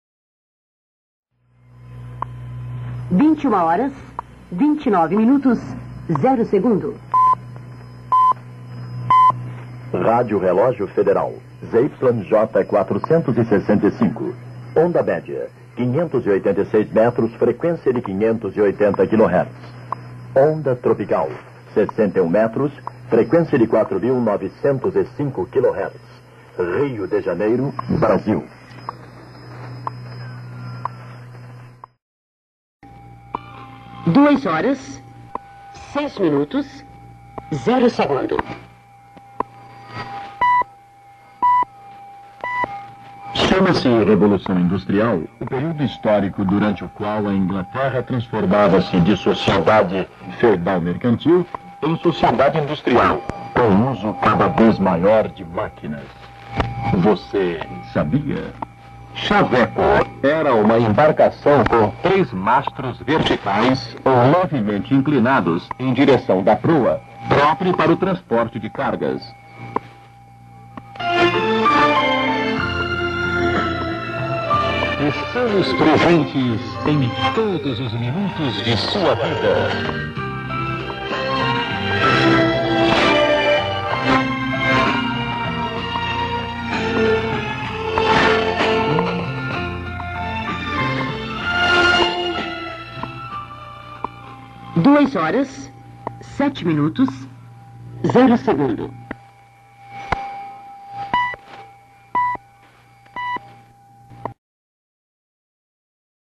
marcada pelo tique-taque de um relógio.
Arquivo sonoro com trecho da programação tradicional da da Rádio Relógio